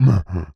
CapersProject/MONSTER_Ugh_04_mono.wav at d0a8d3fa7feee342666ec94fc4a0569fb8c8c2c9
MONSTER_Ugh_04_mono.wav